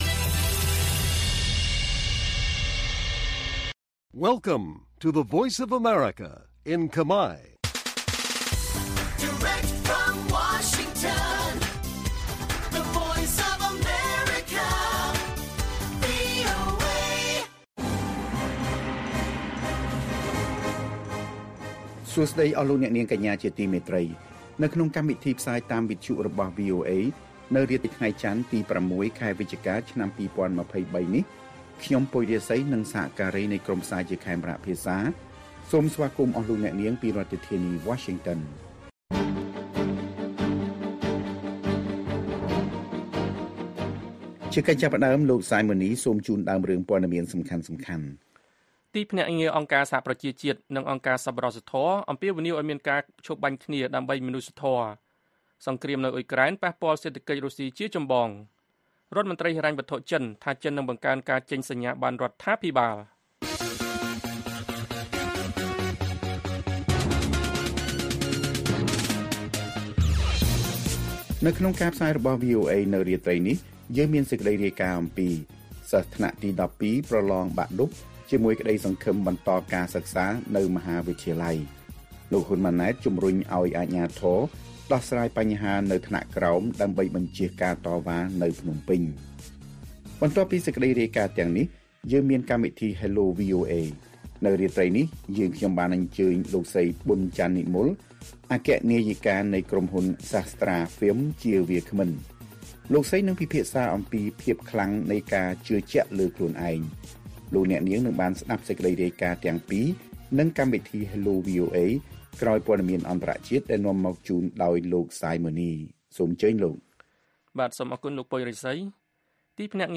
ព័ត៌មានពេលរាត្រី ៦ វិច្ឆិកា៖ ទីភ្នាក់ងារអង្គការសហប្រជាជាតិ និងអង្គការសប្បុរសធម៌អំពាវនាវឱ្យមានការឈប់បាញ់គ្នាដើម្បីមនុស្សធម៌